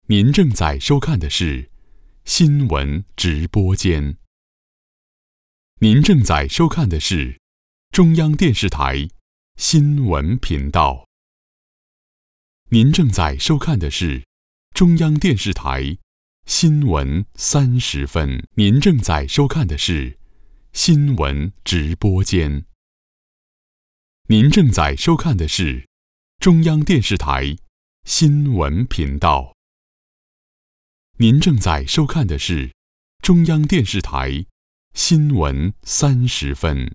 • 男11 国语 男声 台呼 CCTV新闻频道呼号 您正在收看的是中央电视台 激情激昂|科技感|积极向上|时尚活力